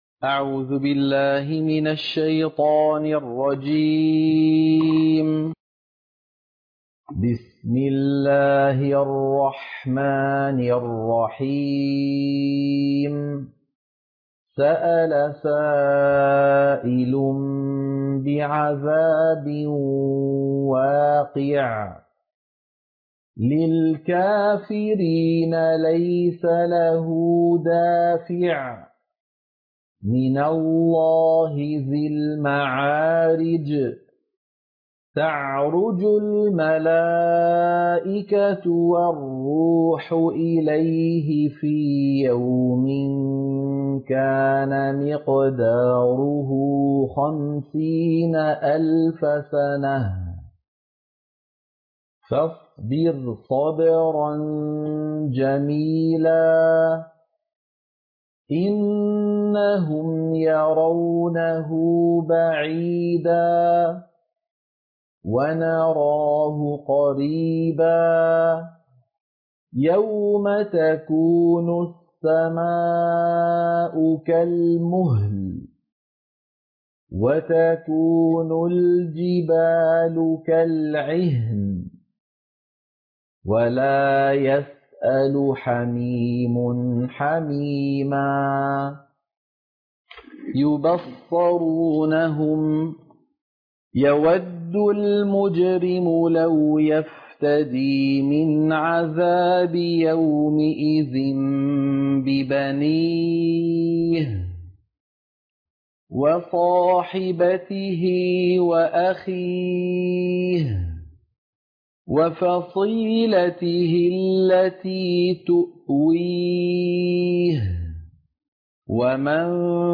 سورة المعارج - القراءة المنهجية - الشيخ أيمن سويد